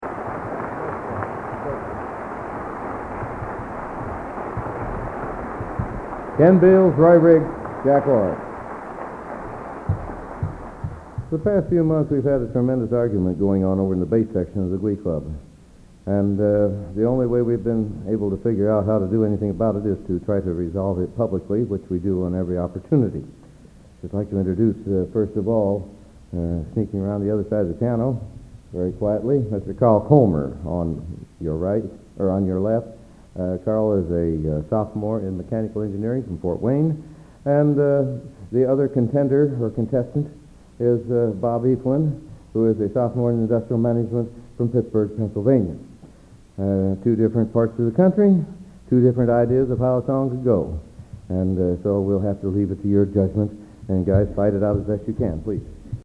Collection: End of Season, 1979
Location: West Lafayette, Indiana
Genre: | Type: Director intros, emceeing